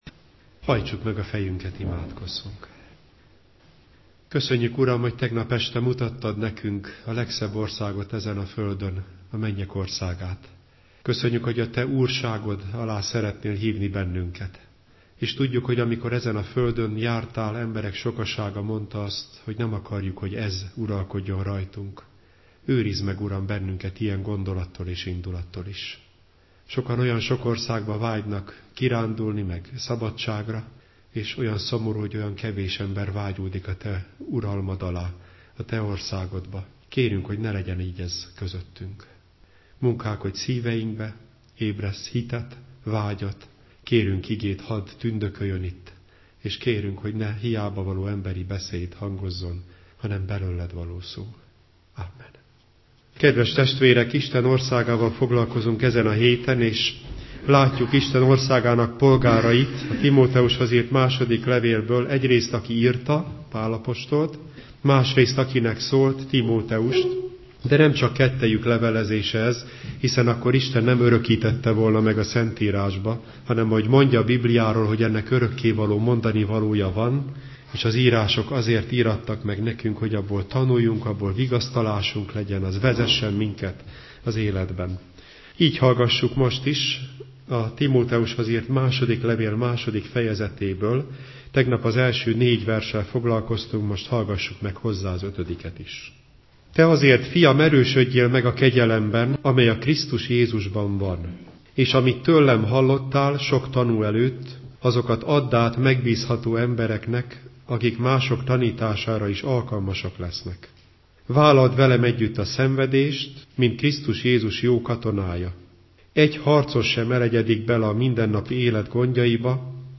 Biatorbágy